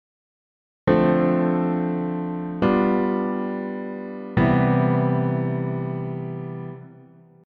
🔽Dm7→G7→CM7（dimコード使用なし）
How-to-use-dim-chord-1.mp3